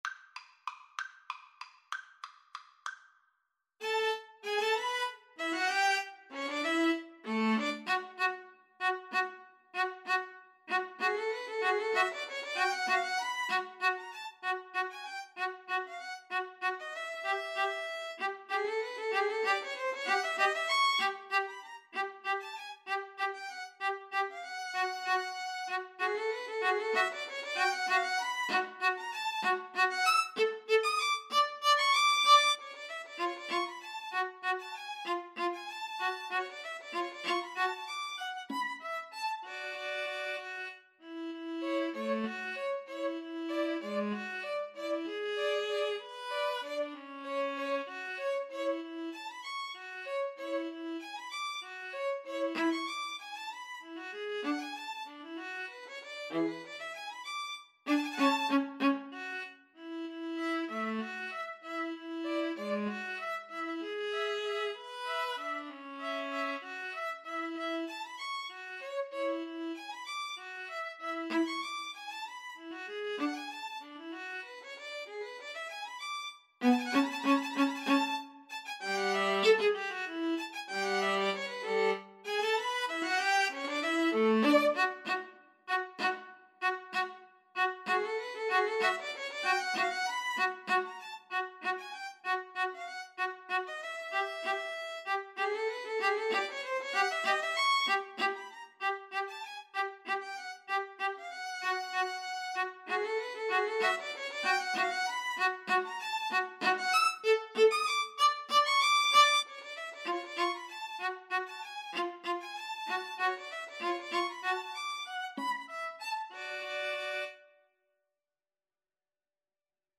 Tempo di Waltz (.=c.64)
Classical (View more Classical String trio Music)